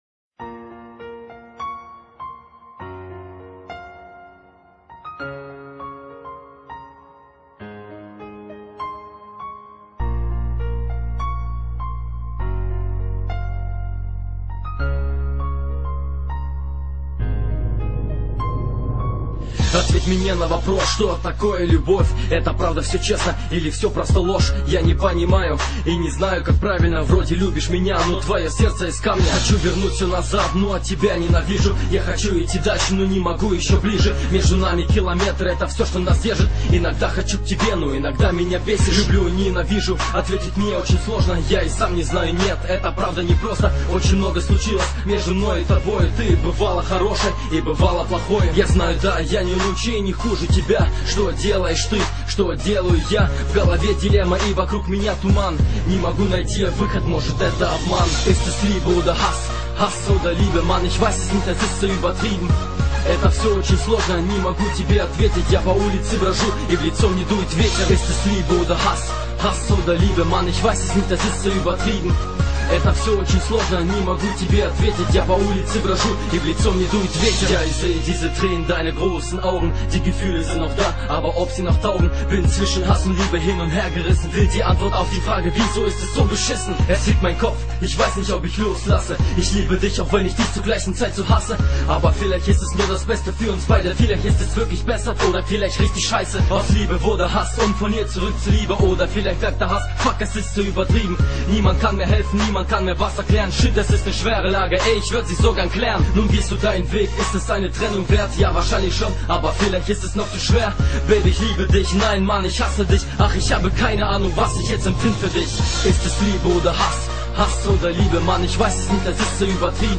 nemeckiy_rep__rep_pro_lyubov_na_rastoyanii.mp3